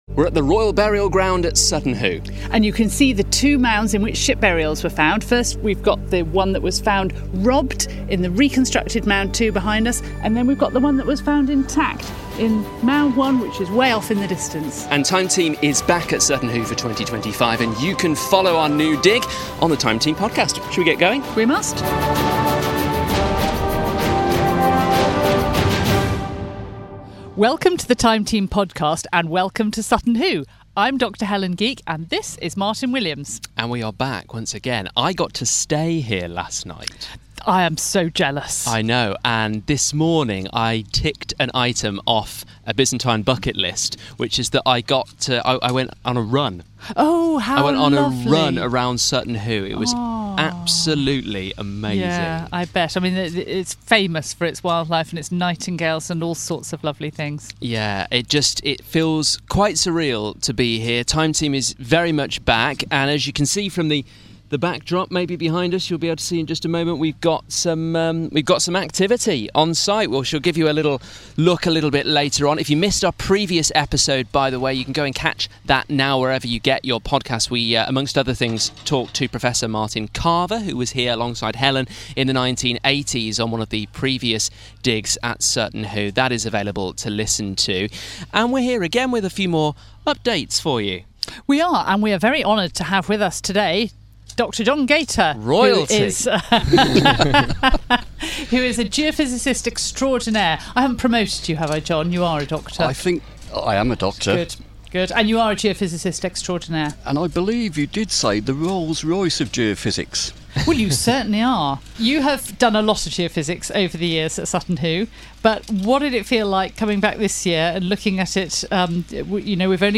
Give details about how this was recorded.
this episode of the Time Team podcast from Sutton Hoo where our second year on site is underway.